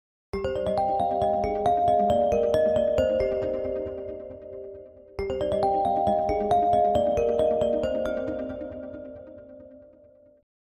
גרסת רמיקס חלופית לצלצול ברירת המחדל הקיים